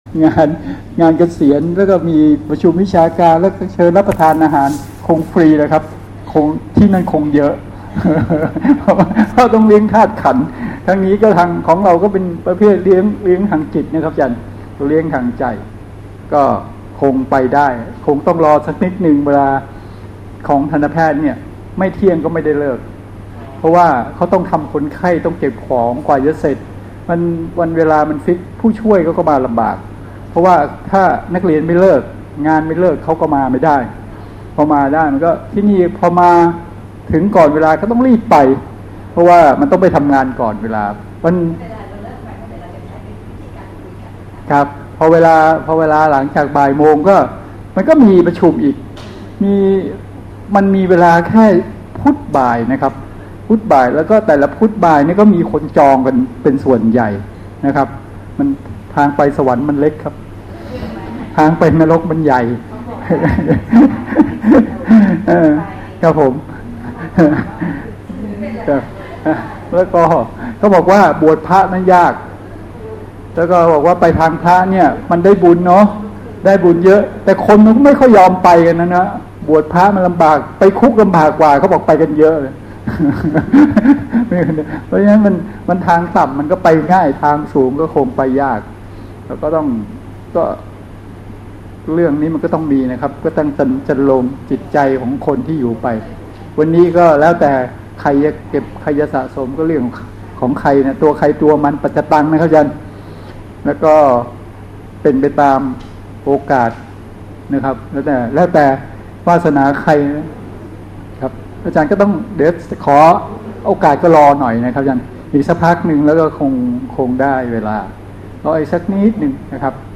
บรรยายธรรม วันที่ 5 ตุลาคม พ.ศ. 2565